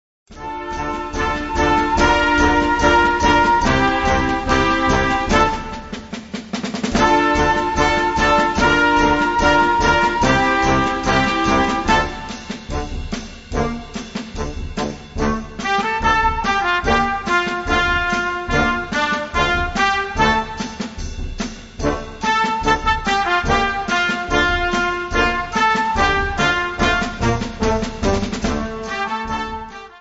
Gattung: Moderne Blasmusik
Besetzung: Blasorchester
Der Rockbeat ist eine gute Stütze fürs Zählen.